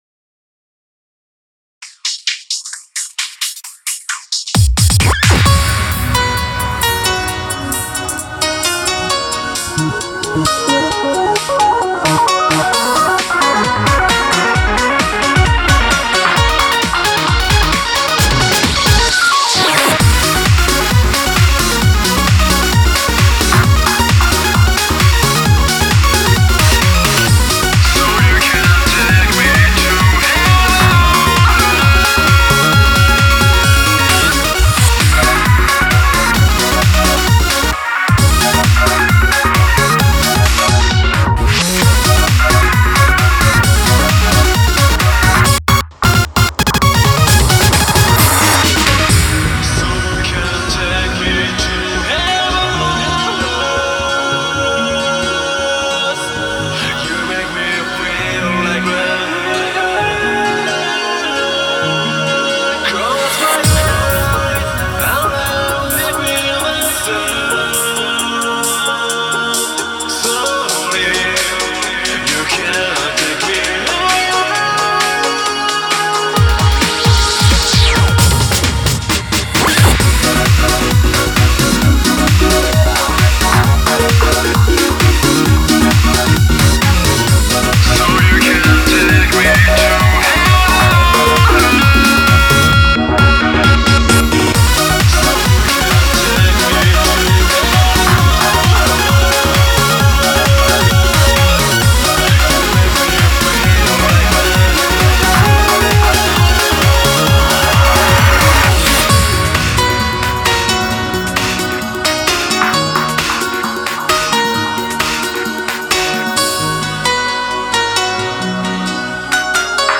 BPM132